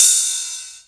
VEC3 Cymbals Ride 17.wav